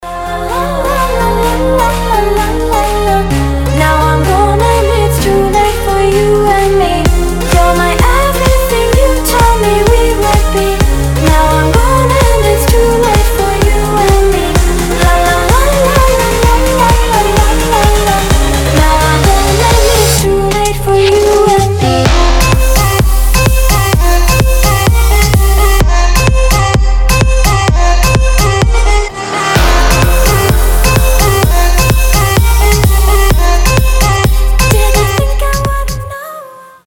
восточные мотивы
dance
Big Room
красивый женский голос